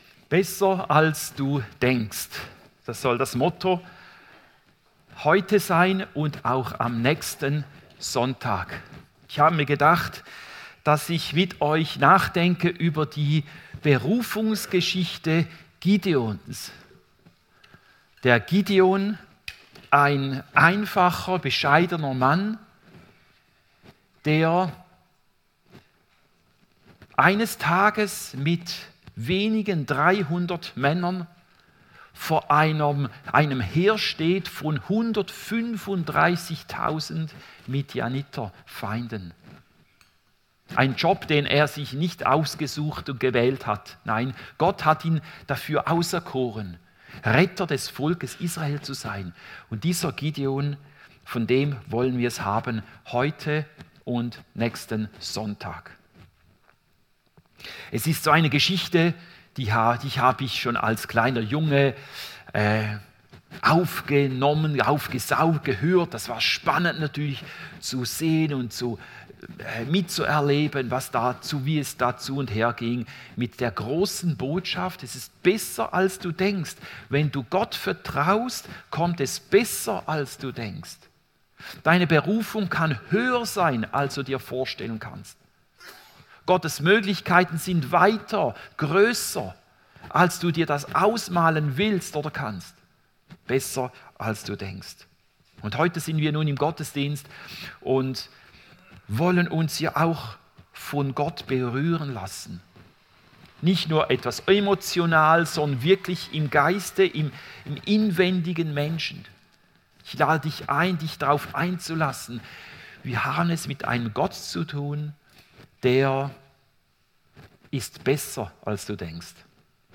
Predigt 16. Juni 2024